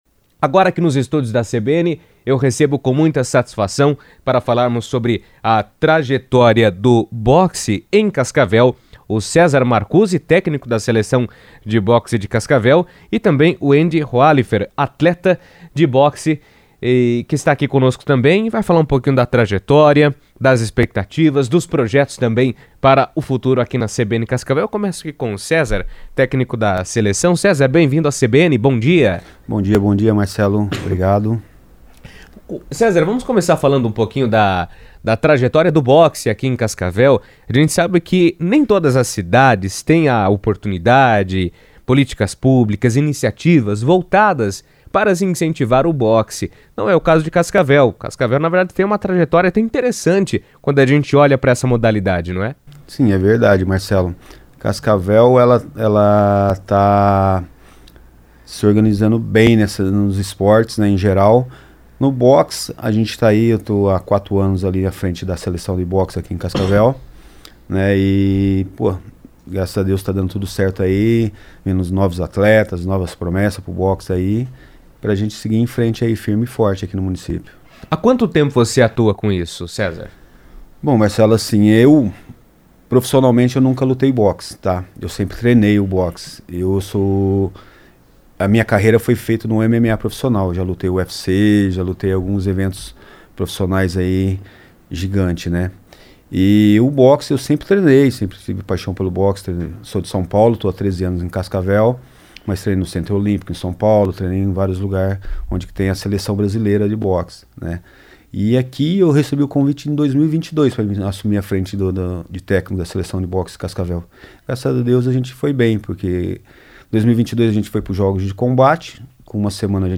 Editoriais